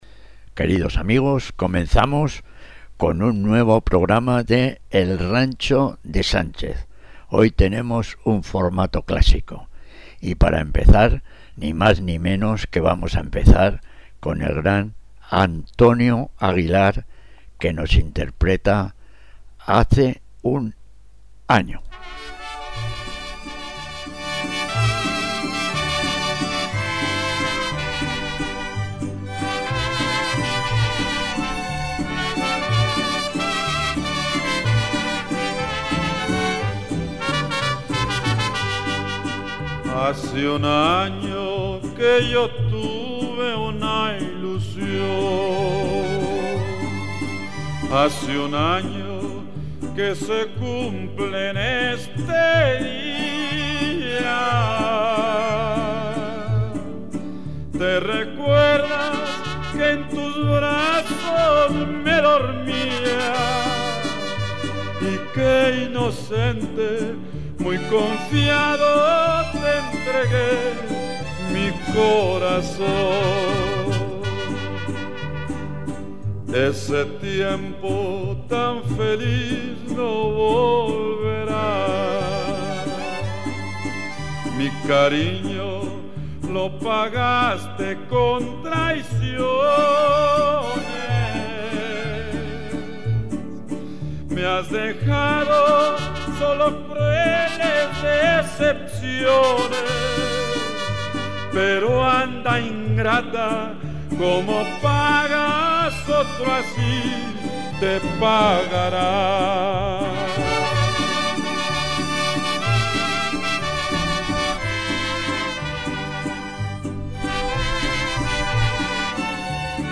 vals peruano